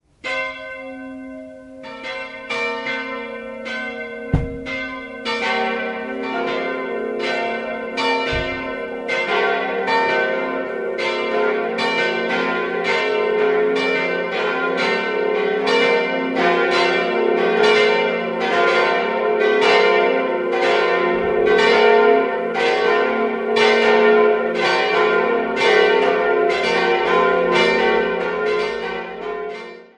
Die heutige Kirche wurde im Jahr 1879 konsekriert, nachdem ein Neubau aufgrund der zu klein gewordenen Schlosskapelle notwendig geworden war. Das Bild im Hochaltar diente auch in der ehemaligen Schlosskapelle als Altarbild. 4-stimmiges Gloria-TeDeum-Geläute: f'-g'-b'-c'' Alle Glocken wurden 1946 von Karl Hamm in Regensburg gegossen.